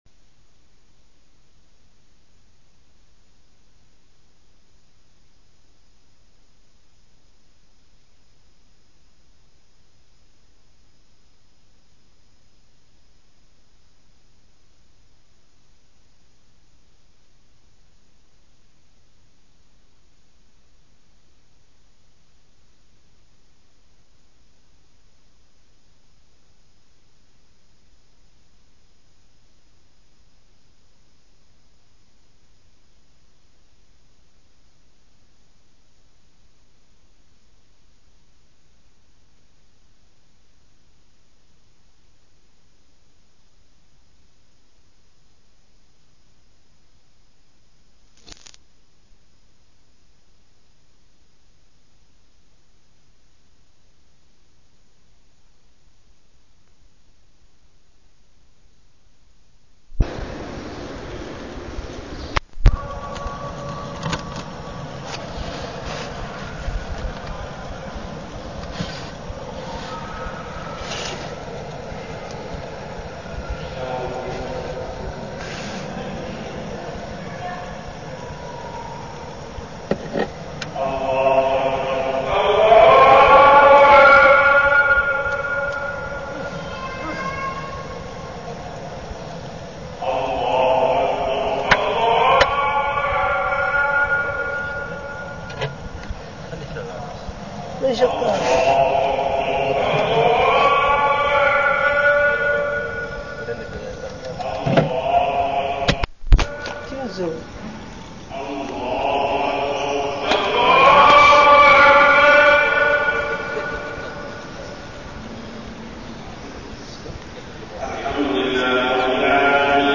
تاريخ النشر ٢٣ جمادى الآخرة ١٤١٢ هـ المكان: المسجد الحرام الشيخ: محمد بن عبد الله السبيل محمد بن عبد الله السبيل الغفلة عن الله من أسباب منع القطر